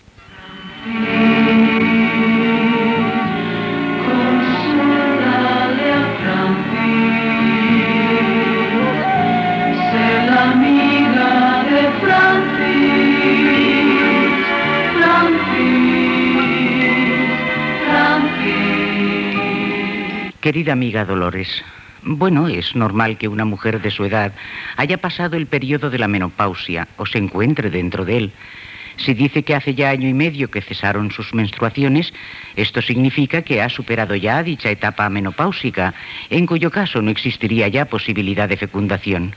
Indicatiu del programa i resposta sobre la menopausa.